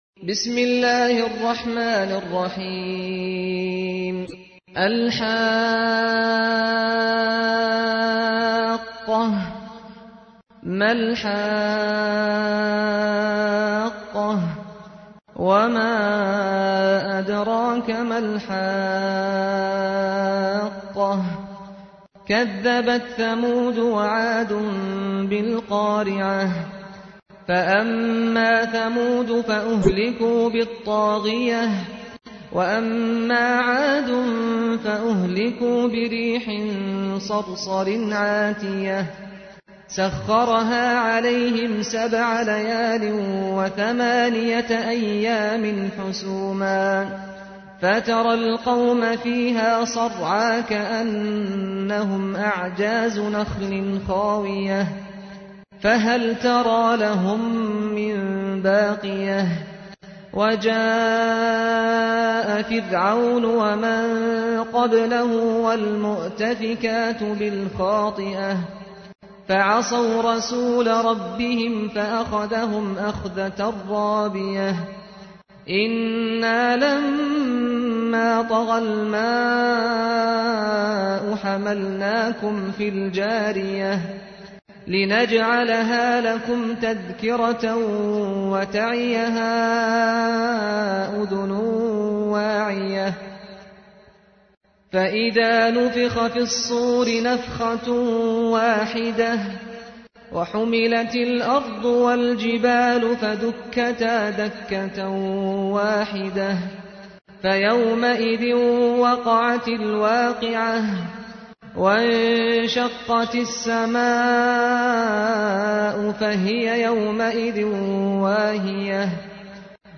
تحميل : 69. سورة الحاقة / القارئ سعد الغامدي / القرآن الكريم / موقع يا حسين